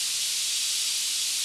Steam_loop.wav